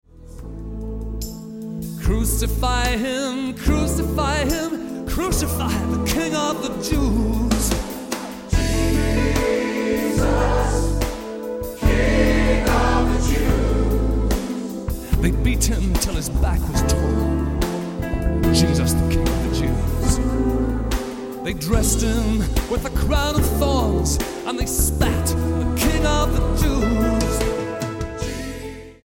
STYLE: Pop
acoustic guitar